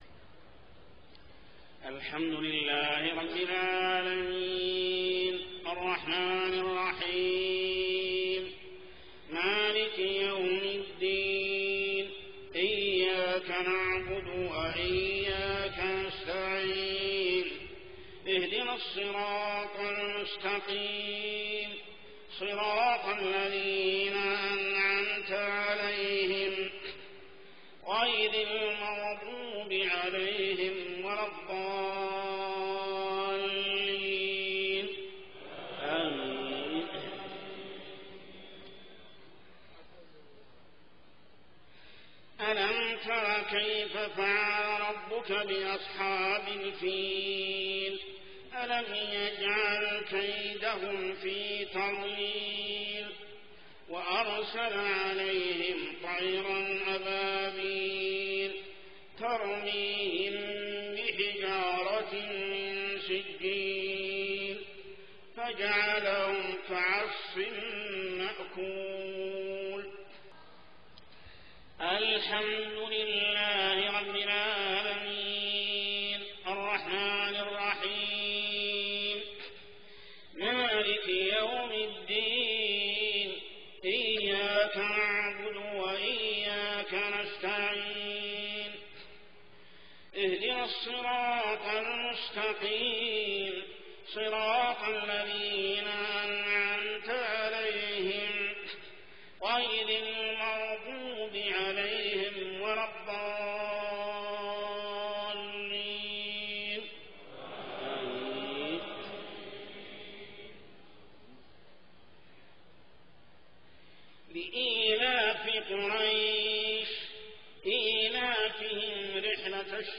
صلاة العشاء 5-5-1427هـ سورتي الفيل و قريش كاملة | Isha prayer Surah Al-Feel and Quraysh > 1427 🕋 > الفروض - تلاوات الحرمين